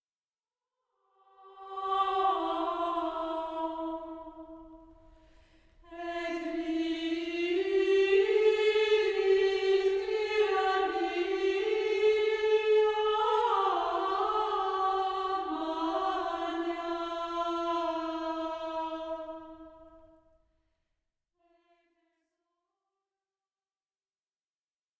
Répons